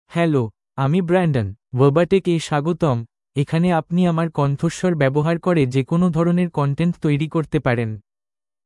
BrandonMale Bengali AI voice
Brandon is a male AI voice for Bengali (India).
Voice sample
Listen to Brandon's male Bengali voice.
Male
Brandon delivers clear pronunciation with authentic India Bengali intonation, making your content sound professionally produced.